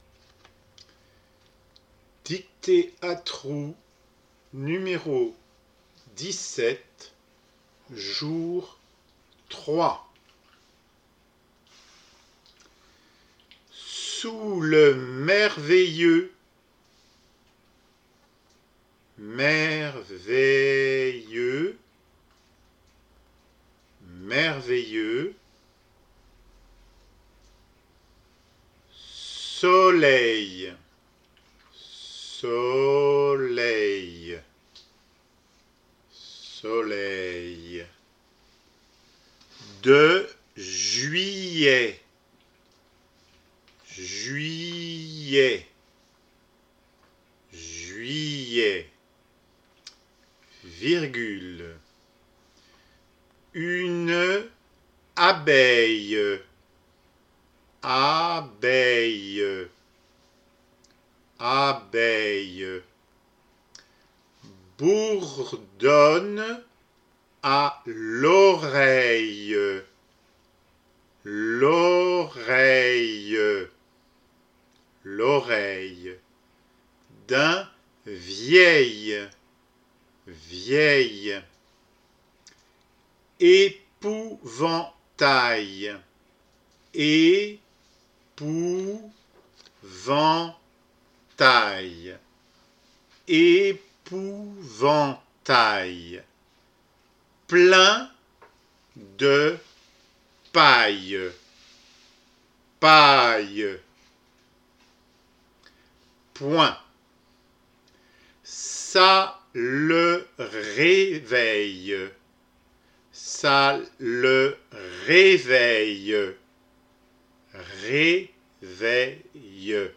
dictée O17 :
Une nouvelle dictée à trous que tu peux écouter ici EN CLIQUANT SUR LE LIEN .Le texte à remplir se trouve en annexe .
dicte_17_j_2.mp3